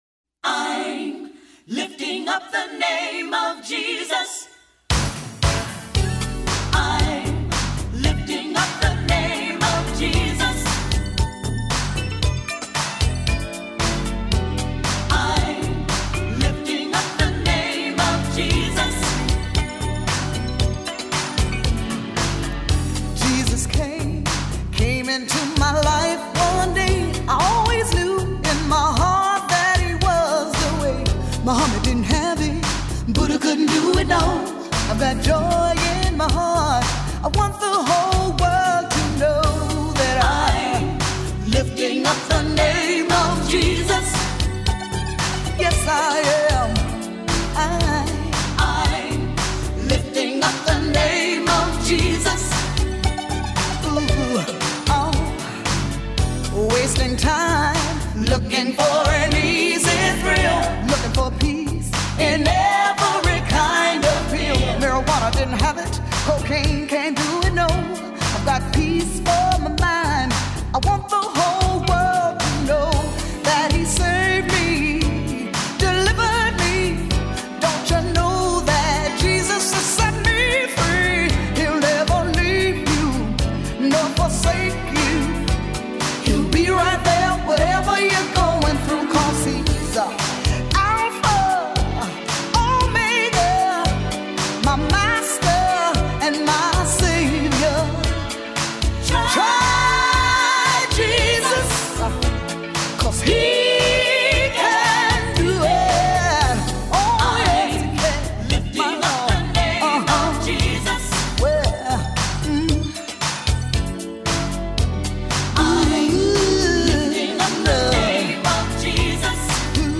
Genre: CCM.